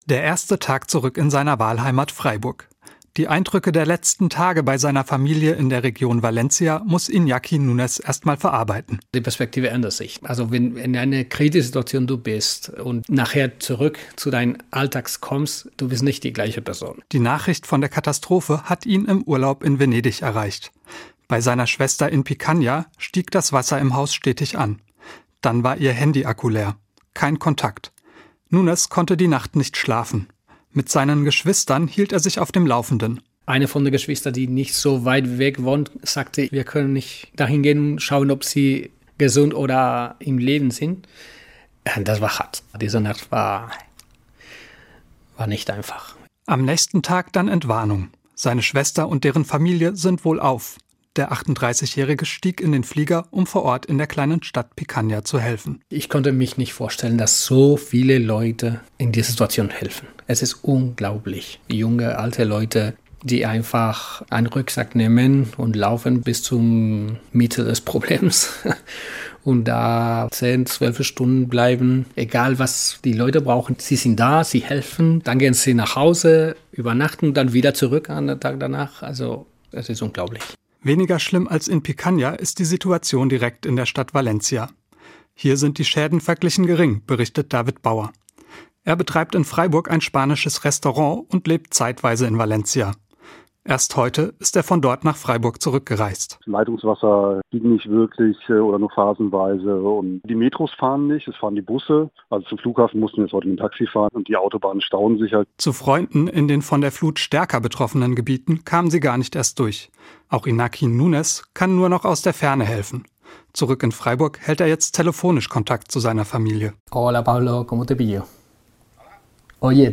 berichtet am Dienstag im SWR Hörfunk über die Eindrücke der Freiburger Helfer: